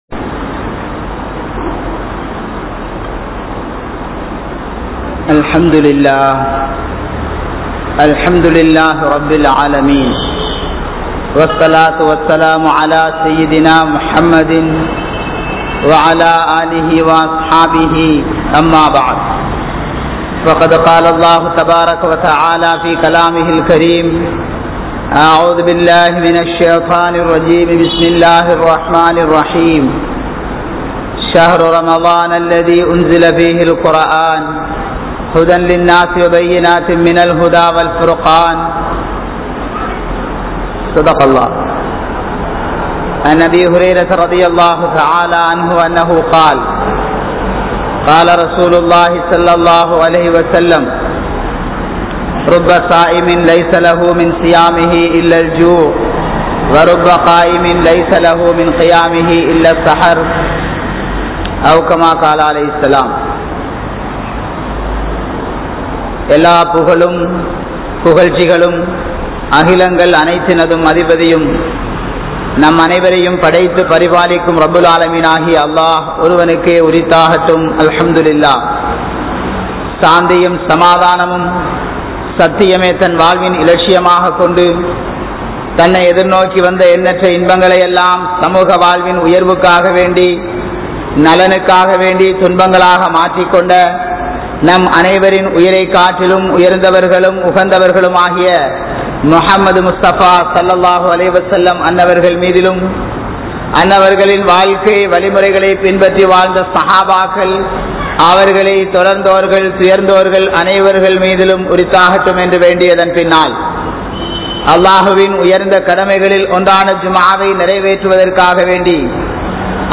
Allah`vin Anpu (அல்லாஹ்வின் அன்பு) | Audio Bayans | All Ceylon Muslim Youth Community | Addalaichenai